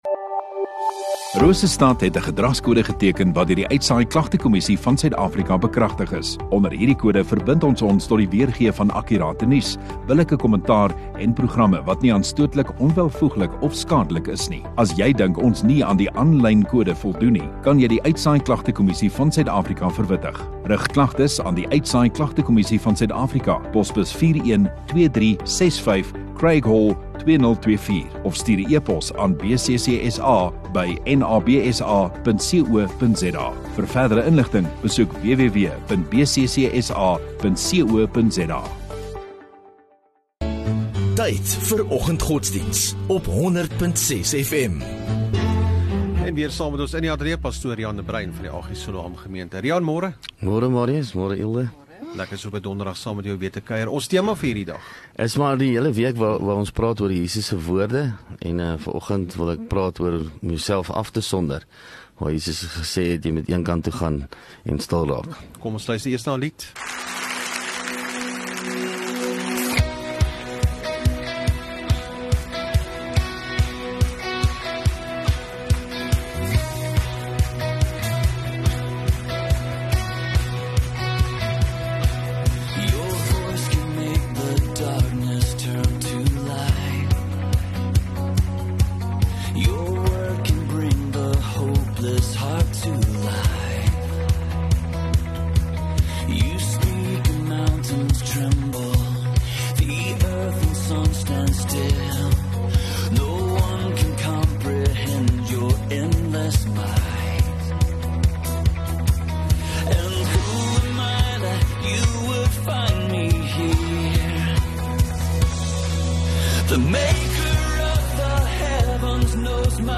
23 Jan Donderdag Oggenddiens